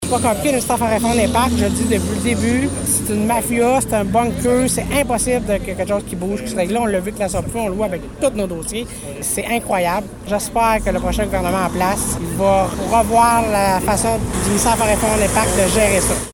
C’était mercredi matin, du côté de Gracefield, que se tenait une conférence de presse rassemblant plusieurs élus de l’Outaouais et des Laurentides. Une mobilisation qui a été organisée afin de crier haut et fort l’importance de l’industrie forestière dans ces deux régions.
C’est le président de la Conférence des Préfets de l’Outaouais, Benoit Lauzon, qui s’est adressé aux gens présents.